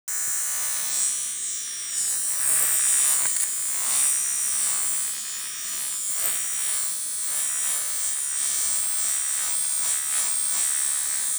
Звук машинки для стрижки волос